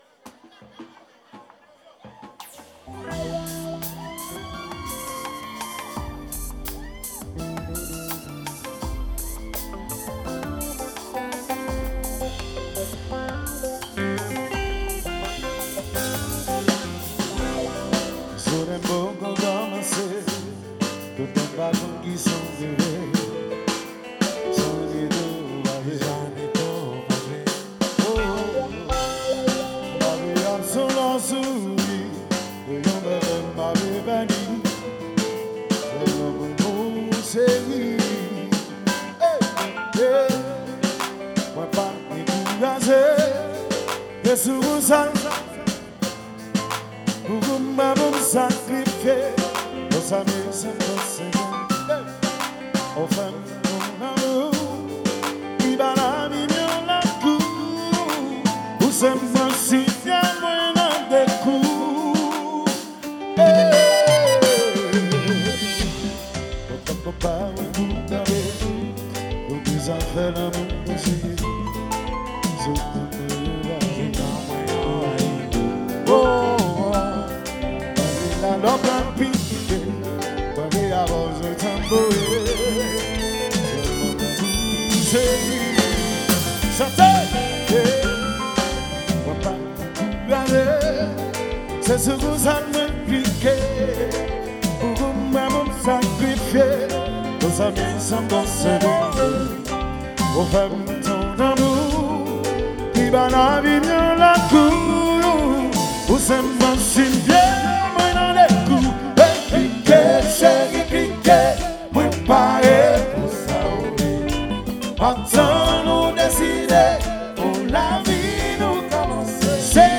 Genre : World Music.